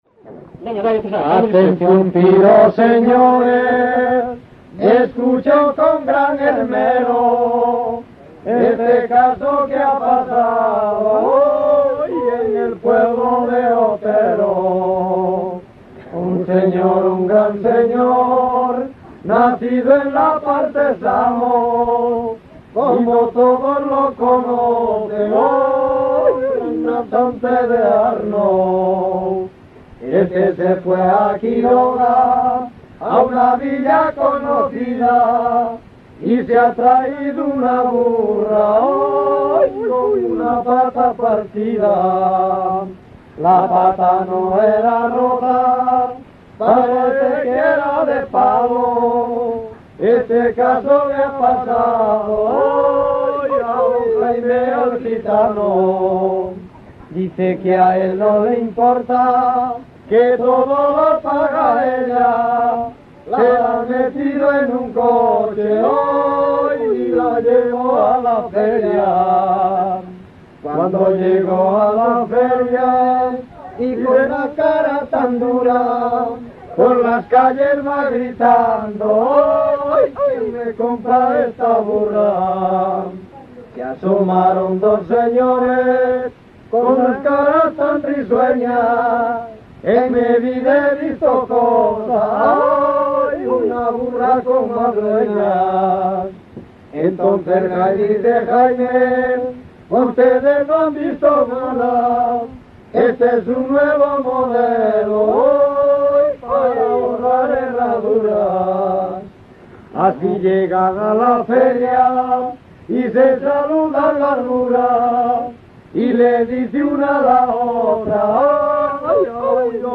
Tipo de rexistro: Musical
Áreas de coñecemento: LITERATURA E DITOS POPULARES > Cantos narrativos
Lugar de compilación: Quiroga - Seara, A - Soldón, O
Soporte orixinal: Casete
Instrumentación: Voz
Instrumentos: Voces masculinas